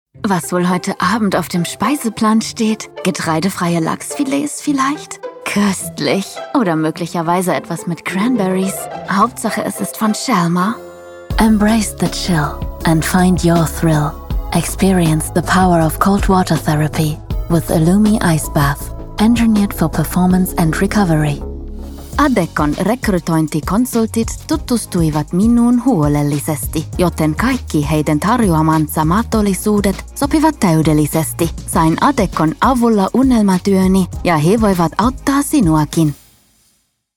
Female TEENS , 20s British English (Native) Bright , Bubbly , Character , Children , Confident , Cool , Engaging , Friendly , Natural , Soft , Streetwise , Warm , Versatile , Young Animation , Audiobook , Character , Commercial , Corporate , Documentary , Educational , E-Learning , Explainer , IVR or Phone Messaging , Narration , Video Game